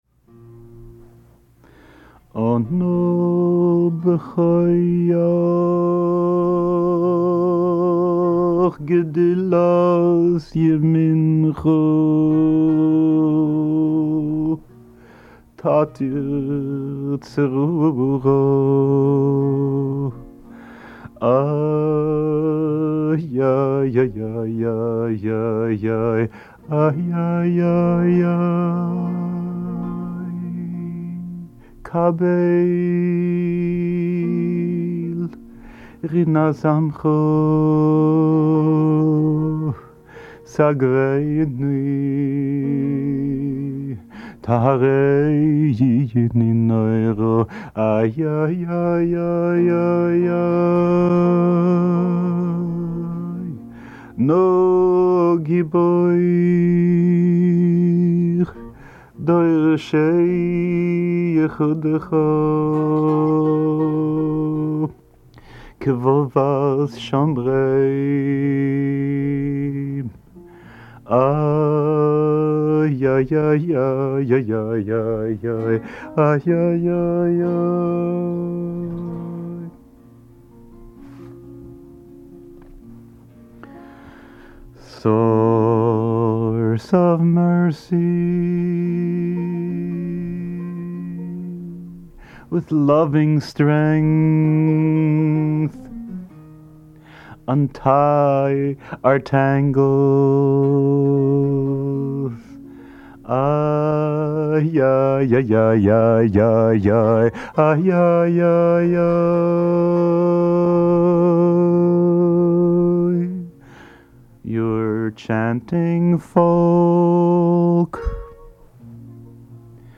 Traditional Ashkenazi melody (sung by Zalman Schachter-Shalomi)